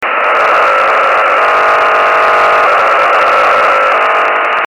Telemetría en el AO-40: